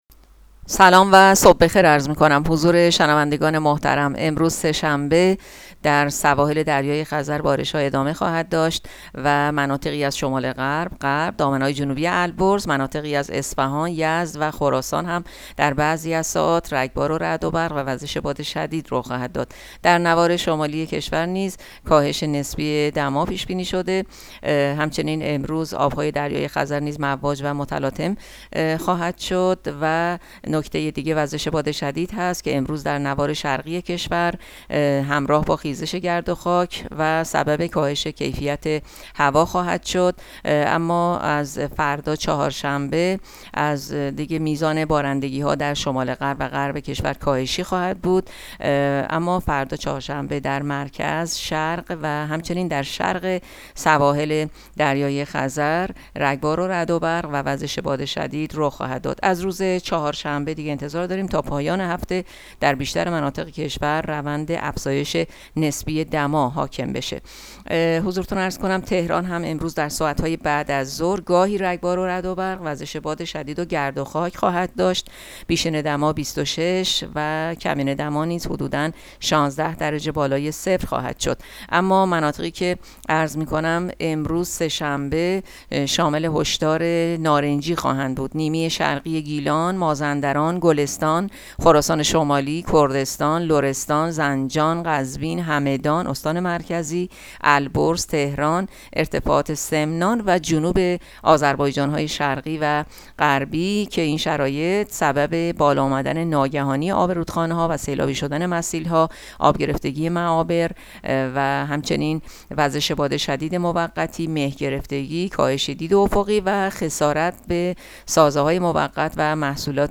گزارش رادیو اینترنتی پایگاه‌ خبری از آخرین وضعیت آب‌وهوای نهم اردیبهشت؛